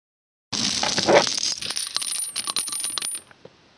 链条
描述：为游戏项目录制的链条声，其中一个装有链钩的怪物正在跟踪玩家
标签： 沙沙 链条 金属 嘎嘎
声道立体声